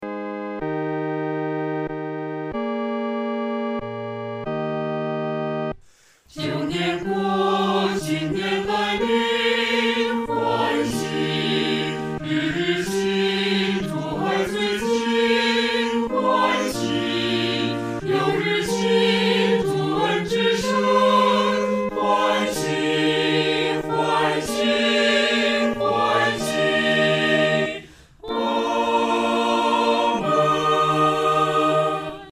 合唱
四声
本首圣诗由网上圣诗班录制